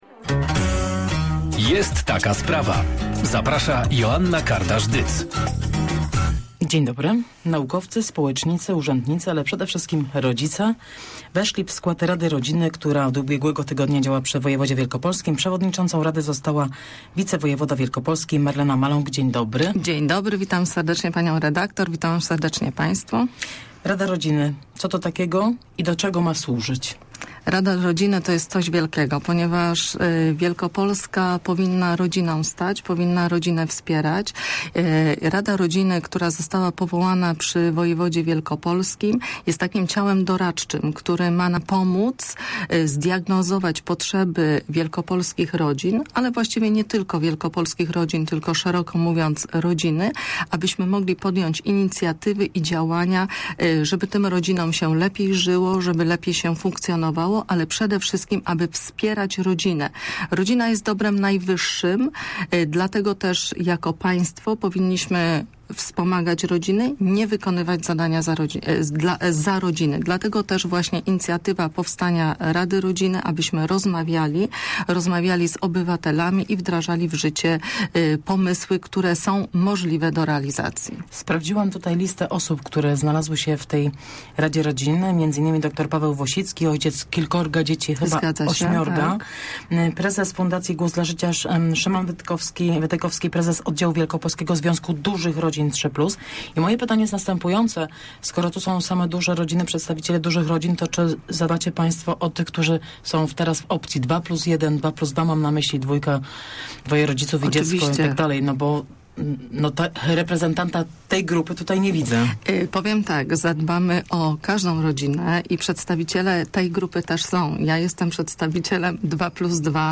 Sto procent samorządów w Wielkopolsce wypłaca już pieniądze z programu 500 plus - powiedziała w porannej rozmowie Radia Merkury wicewojewoda wielkopolski - Marlena Maląg.